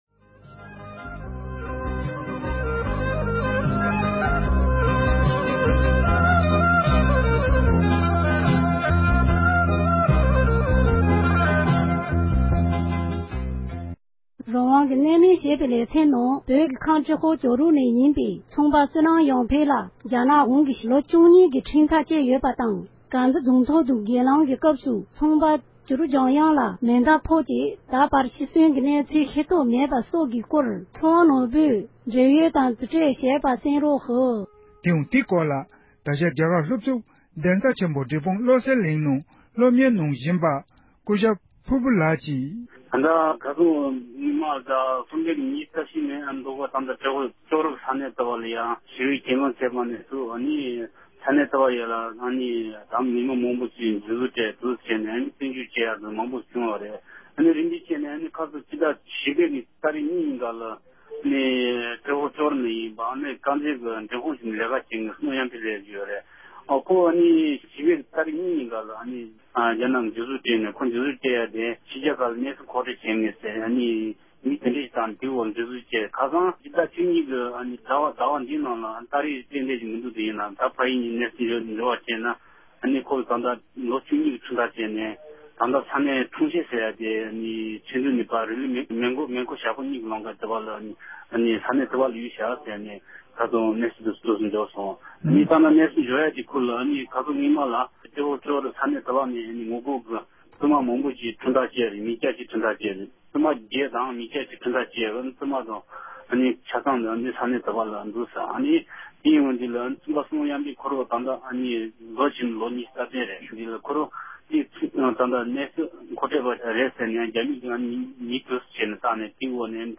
འབྲེལ་ཡོད་མི་སྣར་བཀའ་འདྲི་ཞུས་པ་ཞིག་གསན་རོགས་གནང་